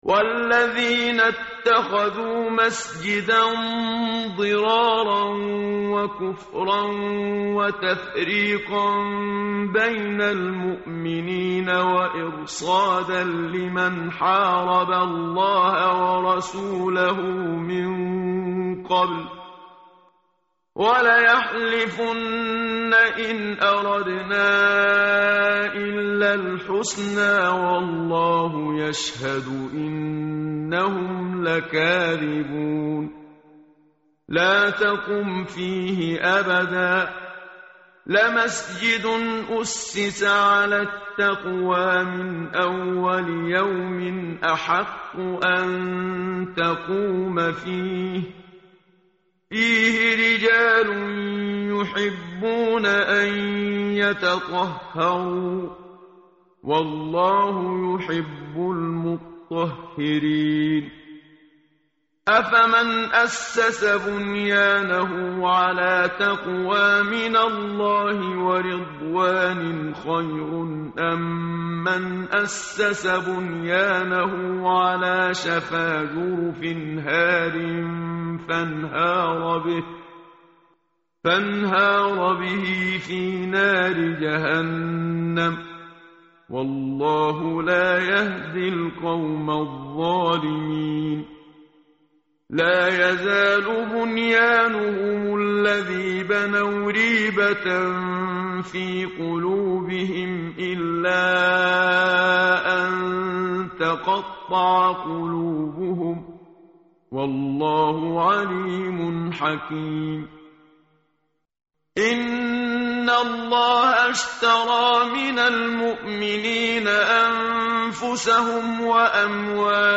متن قرآن همراه باتلاوت قرآن و ترجمه
tartil_menshavi_page_204.mp3